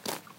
step4.wav